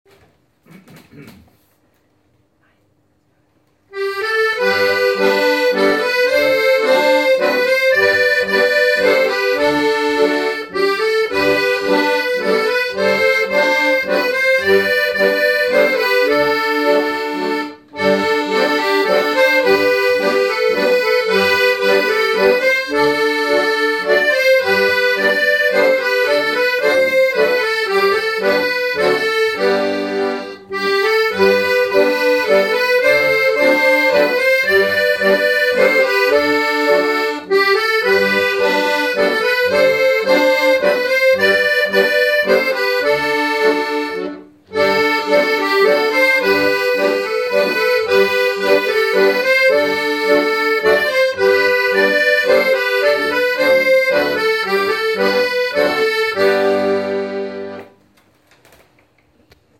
An jedem Adventssonntag wird ein Weihnachtslied veröffentlicht, dass von einer unserer Langenesser Sing - und Musikgruppen
Was soll es bedeuten - die großen Basstölpel